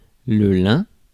Ääntäminen
Synonyymit lin cultivé Ääntäminen France: IPA: [lə lɛ̃] Tuntematon aksentti: IPA: /lɛ̃/ Haettu sana löytyi näillä lähdekielillä: ranska Käännös Substantiivit 1. lina Suku: m .